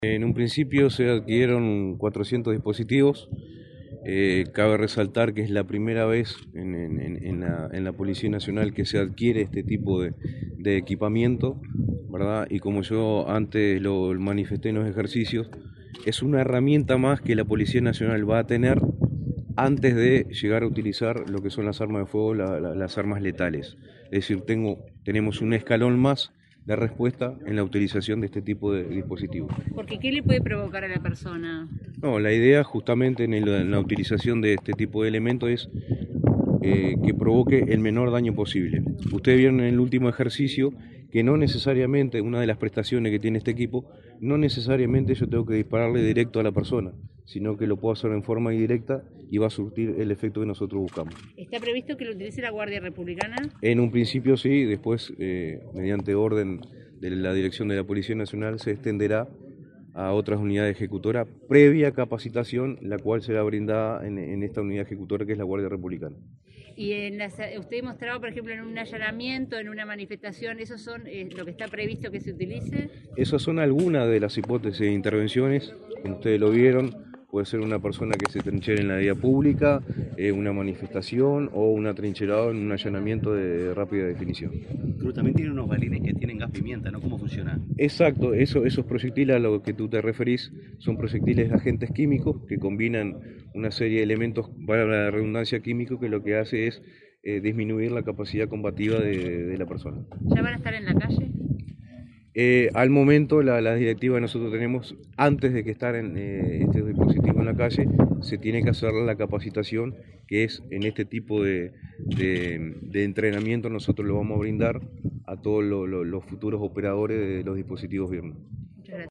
Declaraciones del director nacional de la Guardia Republicana, Rafael Cruz 30/07/2024 Compartir Facebook X Copiar enlace WhatsApp LinkedIn El titular de la Dirección Nacional de la Guardia Republicana, Rafael Cruz, fue entrevistado para medios periodísticos, luego de que el Ministerio del Interior presentara datos acerca de la compra de armas no letales. El acto se realizó en el campo de tiro de esa unidad militar, en Montevideo.